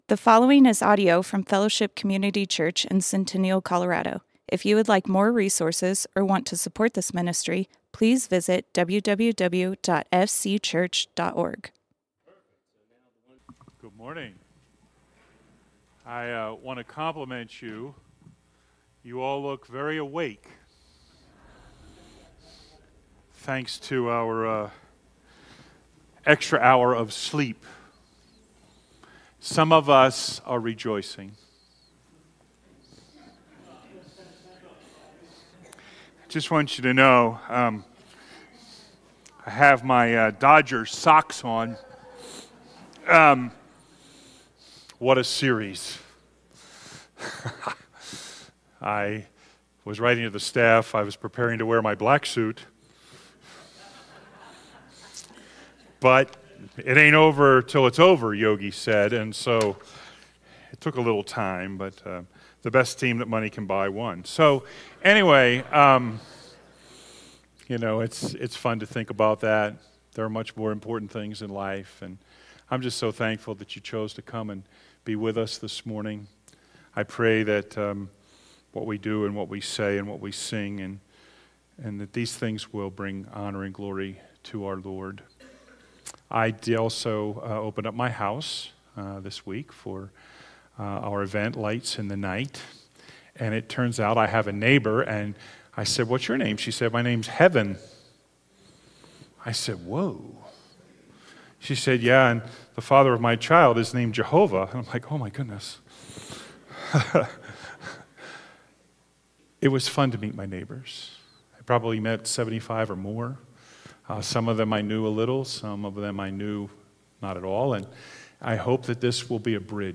Fellowship Community Church - Sermons